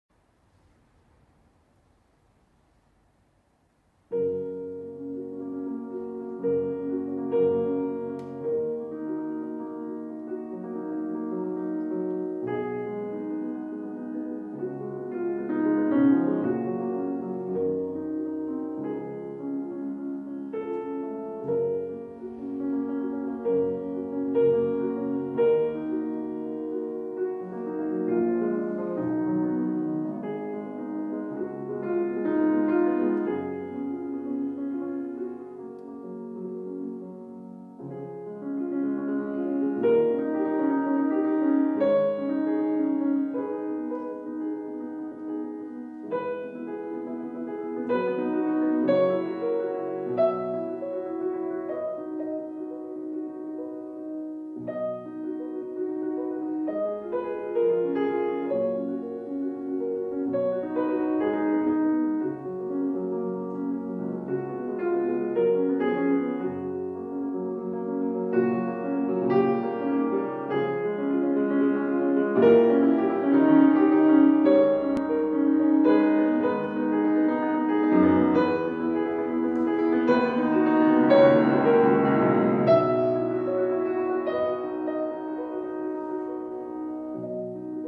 IV OTTOBRE MUSICALE A PALAZZO VALPERGA - Gli Otto Improvvisi di Franz Schubert
piano